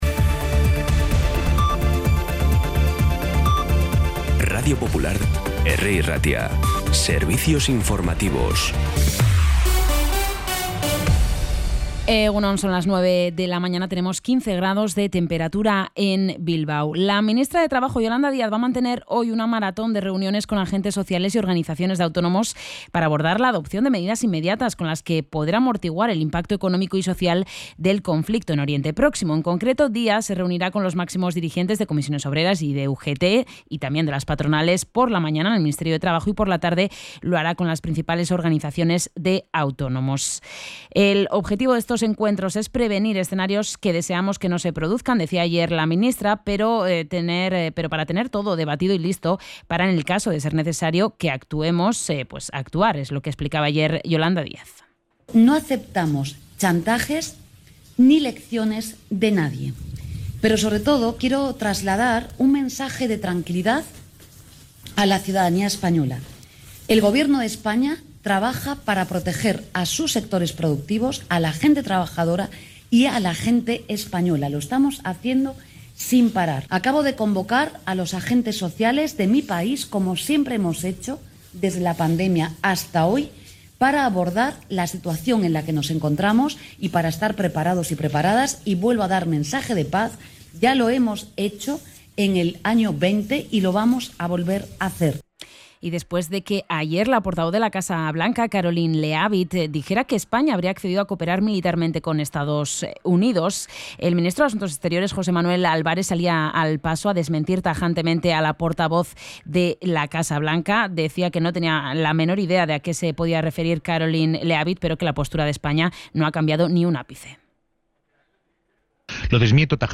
Las noticias de Bilbao y Bizkaia de las 9 , hoy 5 de marzo
Los titulares actualizados con las voces del día. Bilbao, Bizkaia, comarcas, política, sociedad, cultura, sucesos, información de servicio público.